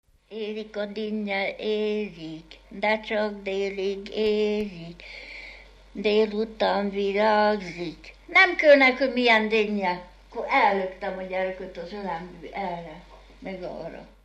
Dunántúl - Tolna vm. - Decs
ének
Műfaj: Gyermekjáték
Stílus: 7. Régies kisambitusú dallamok
Kadencia: b3 (1) b3 1